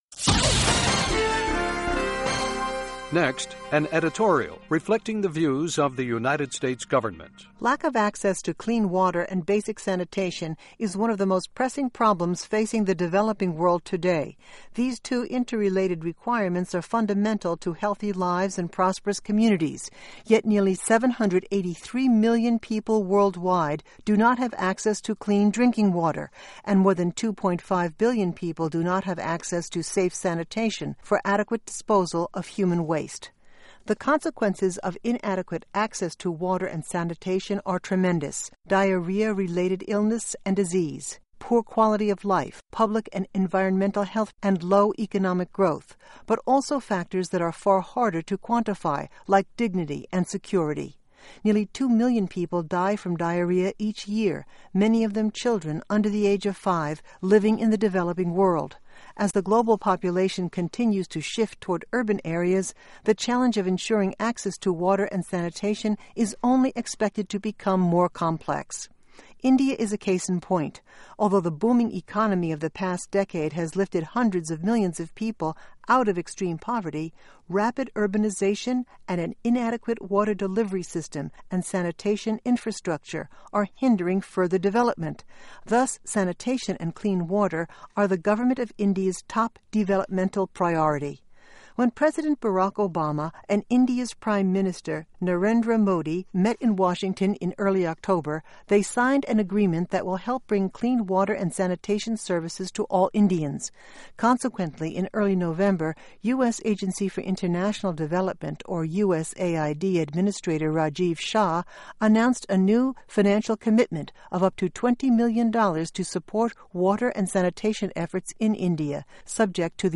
Radio Editorials